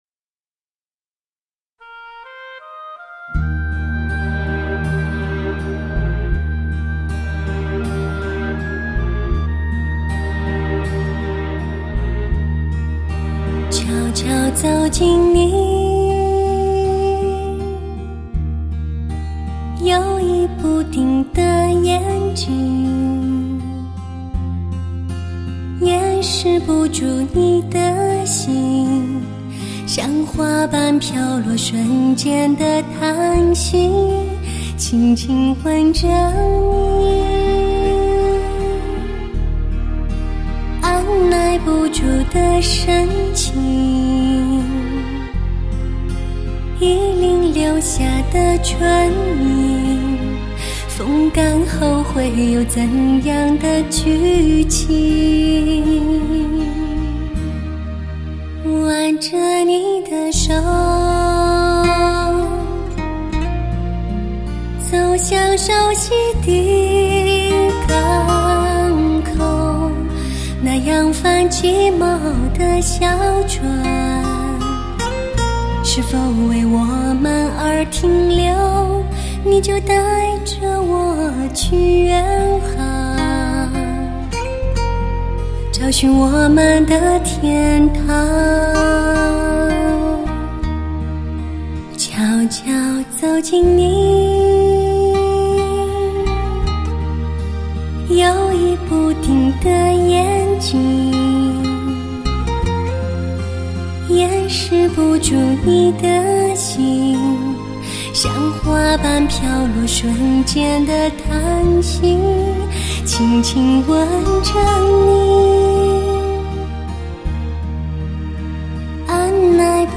收录世上最让人放松的心灵之歌，全都为发烧音乐界强势推荐歌曲
甜美娇嫩的嗓音声线，雕刻出从未有过的现场立体声感
堪称世上最为甜美与感情最为丰富的天使女伶